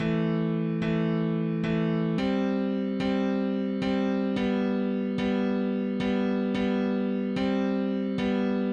钢琴音调彩虹2
Tag: 110 bpm Acoustic Loops Piano Loops 1.47 MB wav Key : C